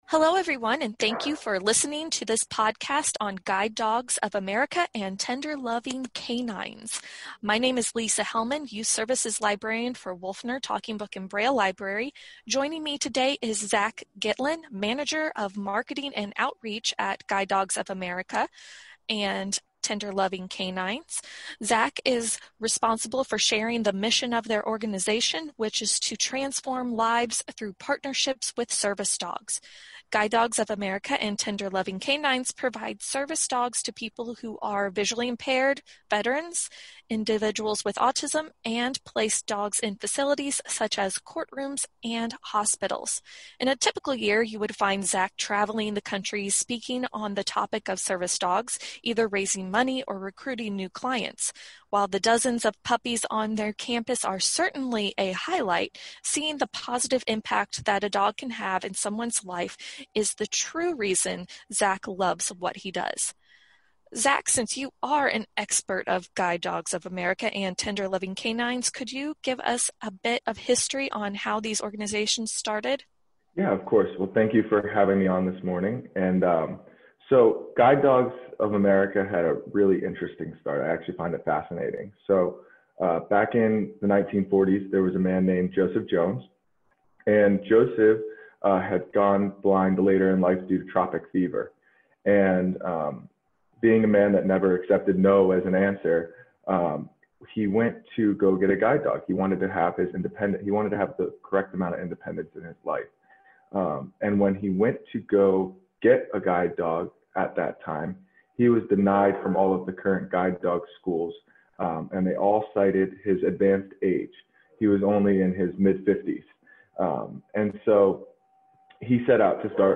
Learn about different topics through interviews with the experts.